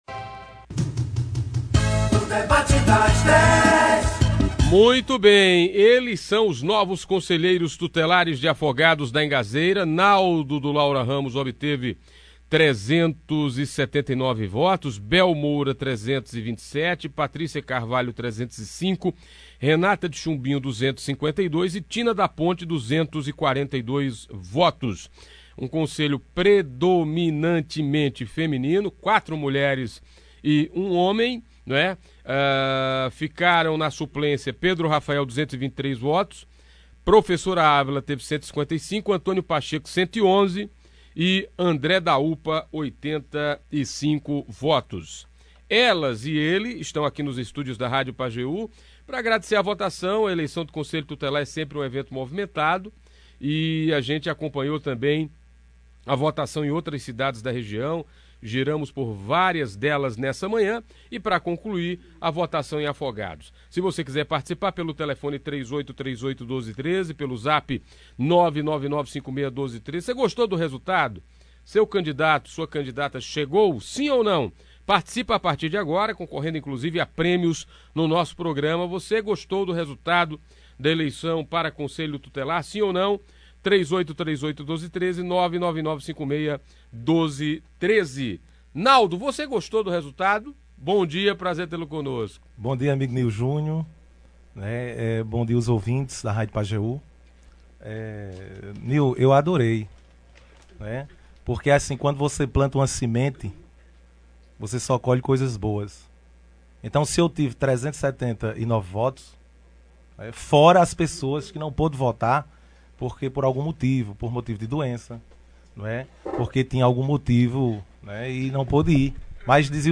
No Debate das Dez da Rádio Pajeú FM 99,3 desta segunda-feira (7), os conselheiros tutelares eleitos na eleição deste domingo (6), avaliaram o pleito e falaram sobre as expectativas do trabalho que assumirão a partir de primeiro de janeiro de 2020.
Ouça abaixo a íntegra do debate de hoje: